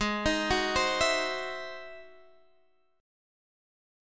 Ab7b13 Chord